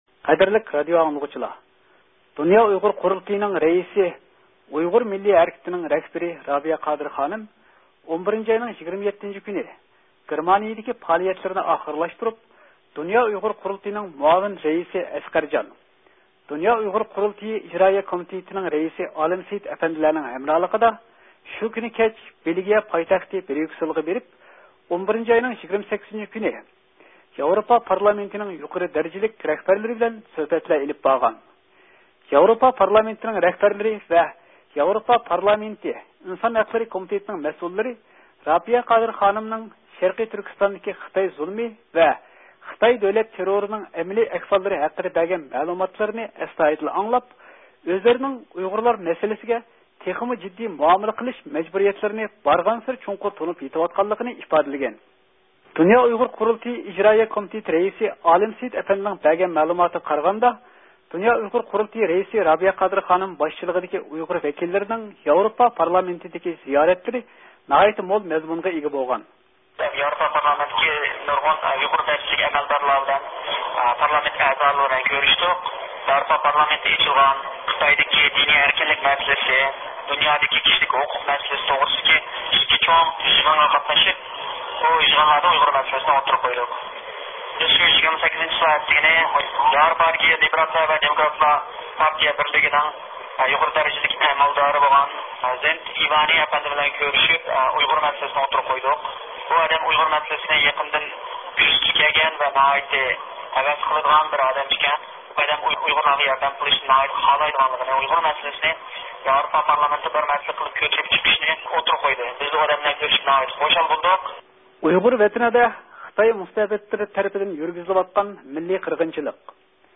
خەۋەرنىڭ